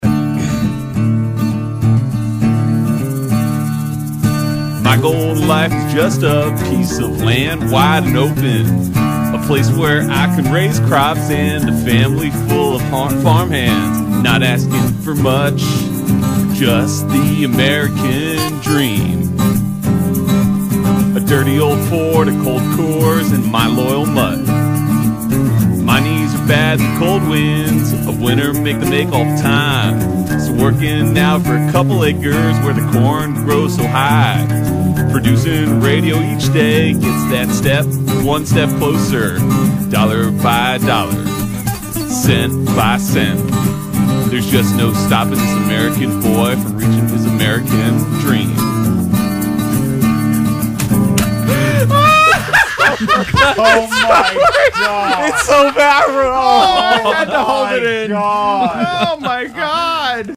Country Songs